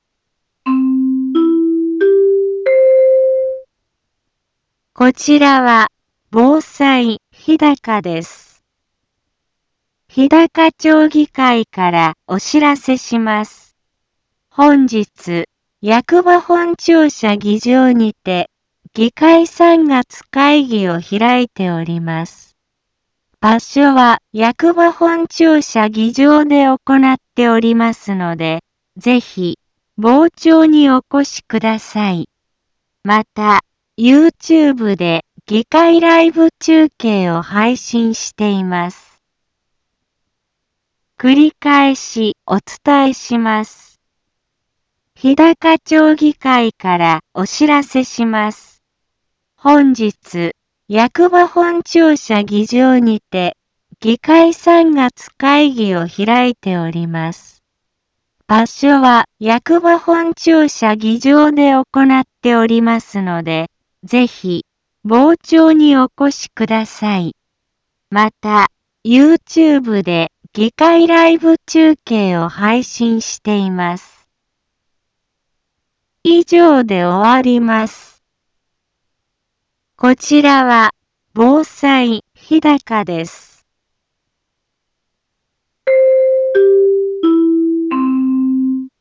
一般放送情報